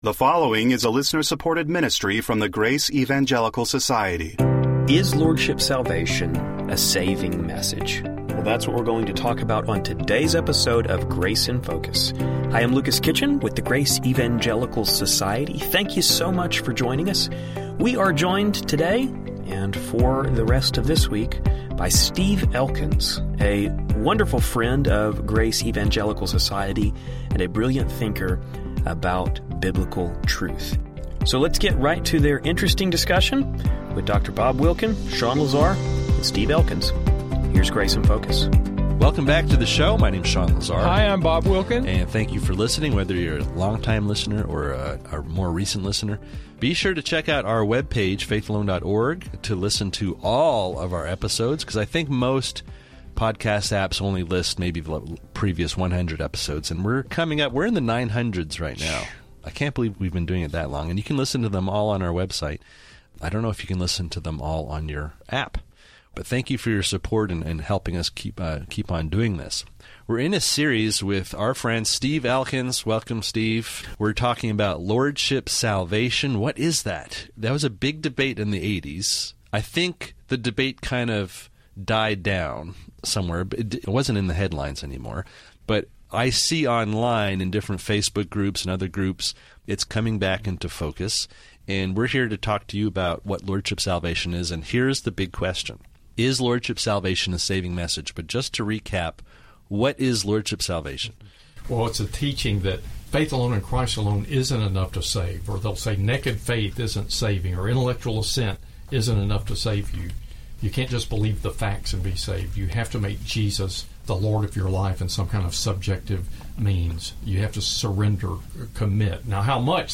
Passages such as Matthew 7:21-23, Romans 4:5, and John 5:39-40 will be addressed in light of this discussion. We hope you find the conversation helpful.